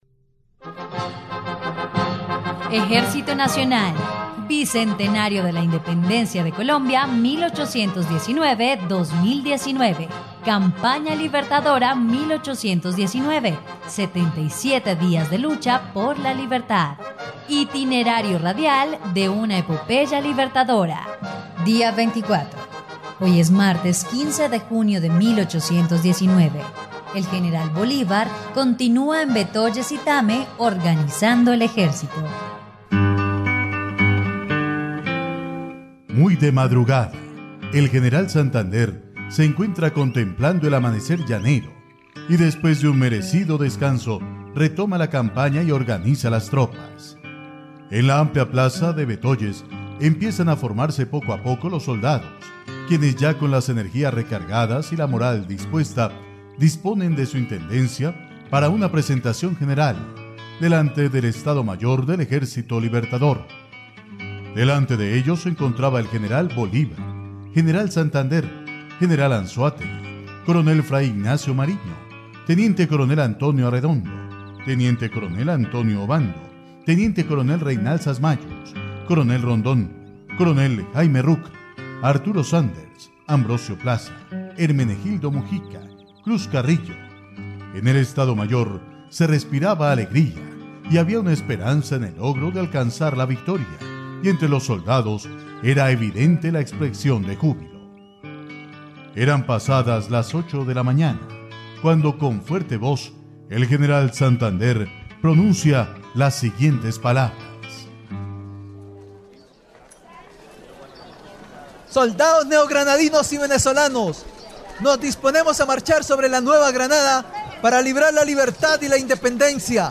dia_24_radionovela_campana_libertadora.mp3